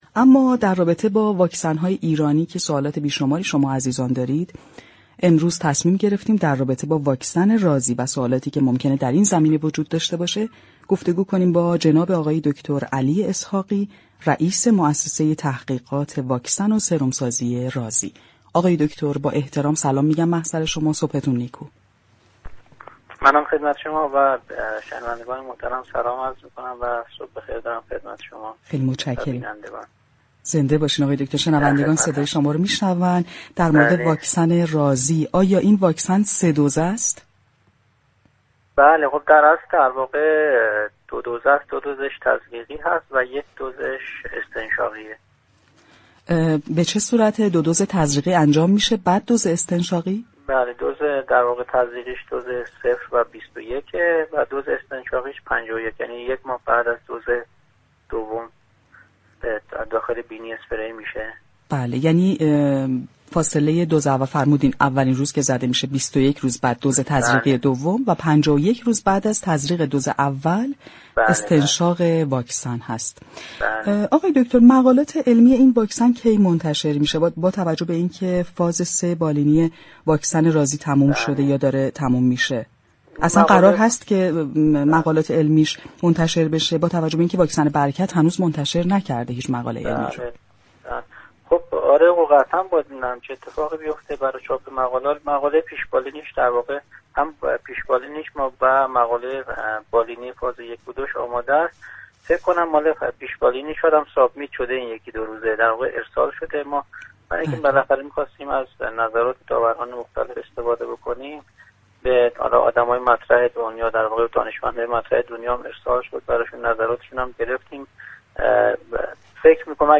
به گزارش پایگاه اطلاع رسانی رادیو تهران، دكتر علی اسحاقی رئیس موسسه تحقیقات واكسن و سرم سازی رازی در گفتگو با برنامه تهران ما سلامت رادیو تهران درباره واكسن رازی گفت: این واكسن در سه دُز ساخته شده است.